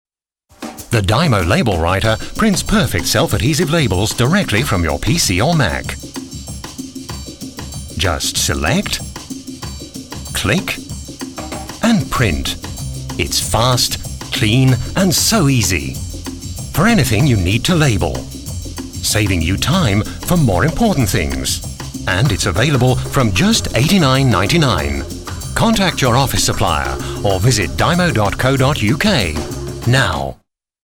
Deutsch spricht er mit leichtem englischem Akzent.
Sprecher englisch uk.
Sprechprobe: Werbung (Muttersprache):
voice over artist english (uk)